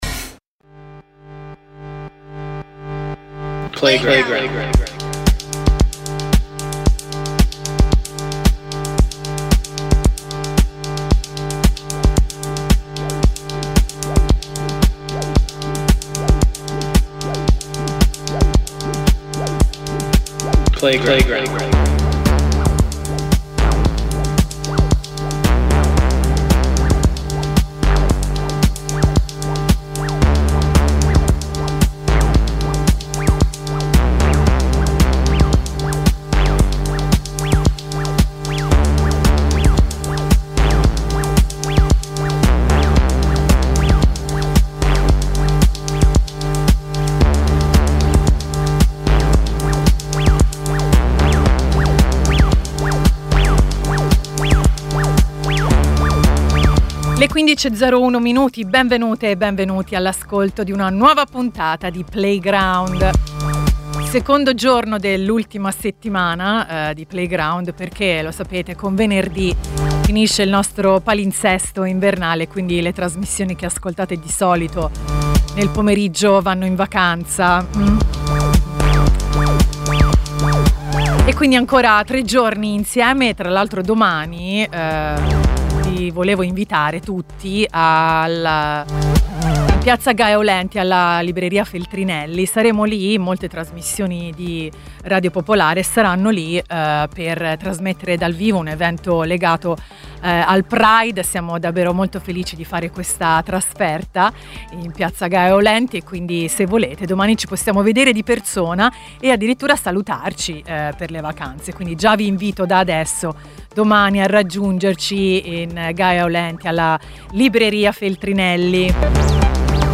A Playground ci sono le città in cui abitiamo e quelle che vorremmo conoscere ed esplorare. A Playground c'è la musica più bella che sentirai oggi. A Playground ci sono notizie e racconti da tutto il mondo: lo sport e le serie tv, i personaggi e le persone, le ultime tecnologie e le memorie del passato.